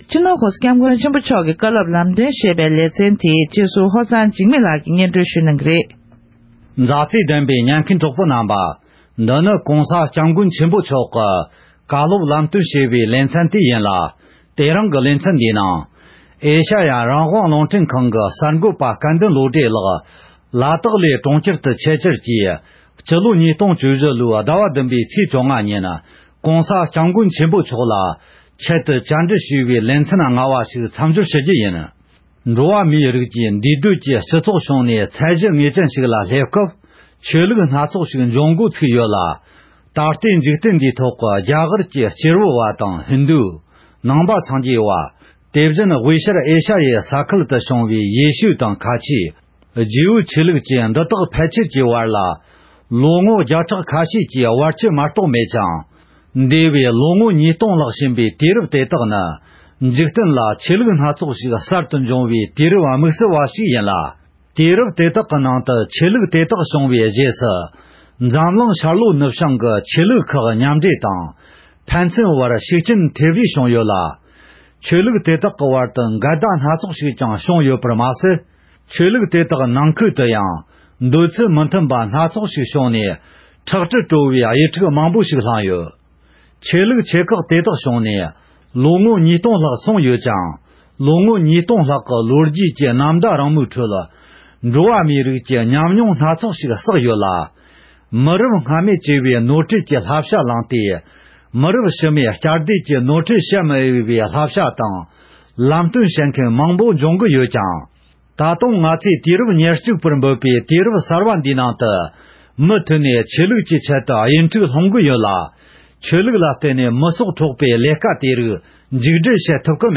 ༸མགོན་པོ་གང་ཉིད་ནས་ཉེ་འཆར་ཕྱི་ཟླ་༧ཚེས་༡༥ཉིན་ཨེ་ཤེ་ཡ་རང་དབང་རླུང་འཕྲིན་ཁང་གི་དམིགས་བསལ་བཅར་འདྲི་དགོངས་སྐོར་ཞུས་པ་ལས་ཟུར་འདོན་ཞུས་པ་ཞིག་དང་། ཡང་ཤི་ལོང་མི་མང་ལ་བཀའ་སློབ་གནང་བའི་ལེ་ཚན་བཅས་སྙན་སྒྲོན་ཞུ་རྒྱུ་ཡིན།